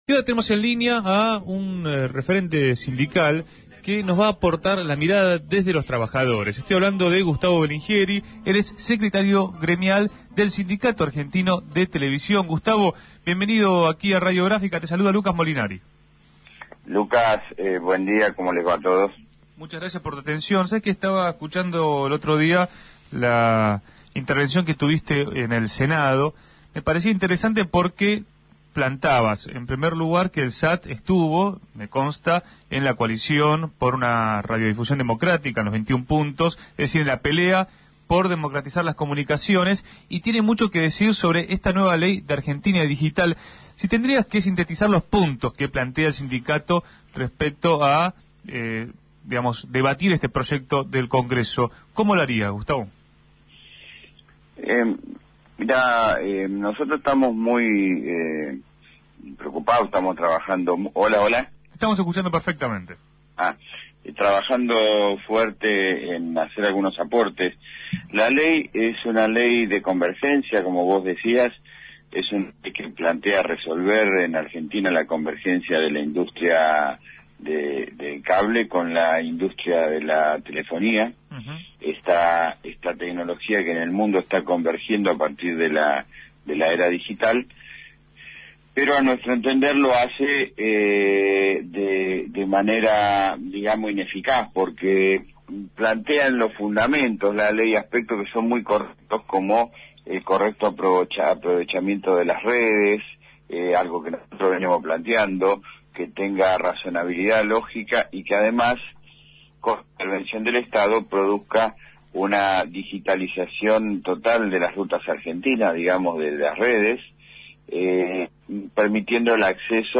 En comunicación con Punto de Partida, dejó asentada la posición de los trabajadores nucleados en ese gremio sobre Argentina Digital.